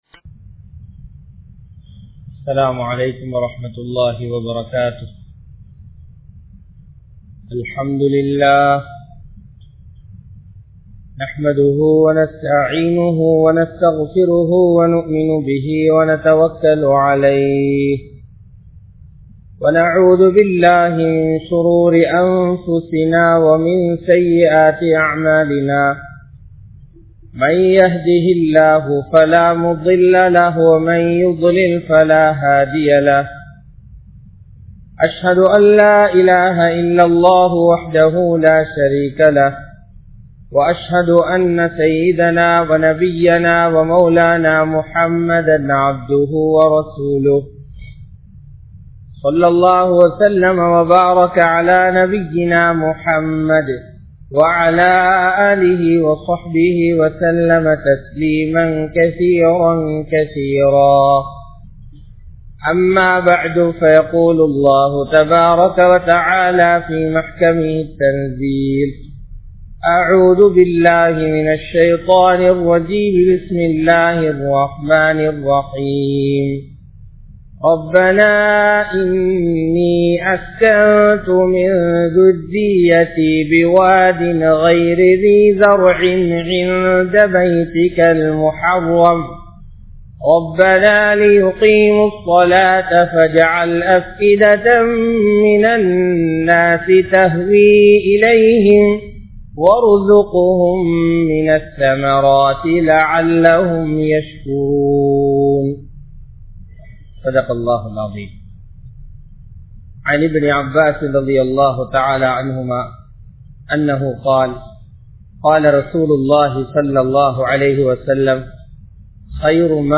Zam Zam Neerin Atputham (ஸம் ஸம் நீரின் அற்புதம்) | Audio Bayans | All Ceylon Muslim Youth Community | Addalaichenai
Dehiwela, Muhideen (Markaz) Jumua Masjith